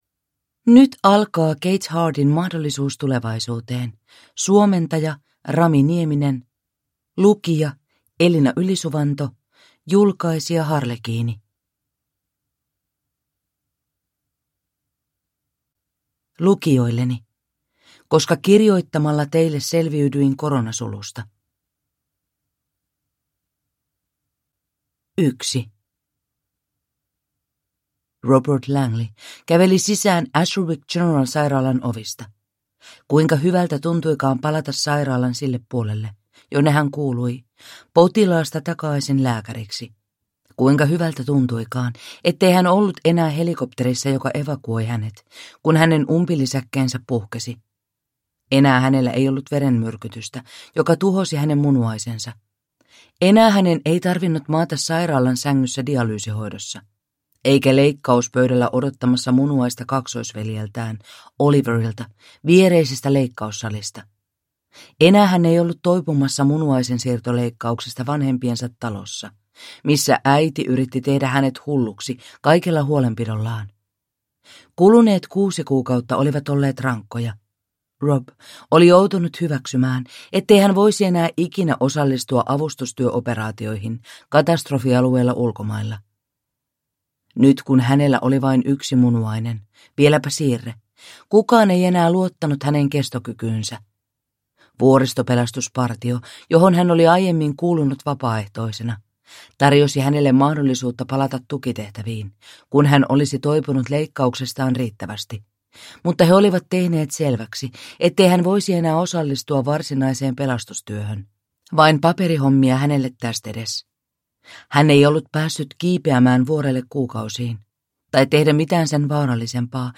Mahdollisuus tulevaisuuteen (ljudbok) av Kate Hardy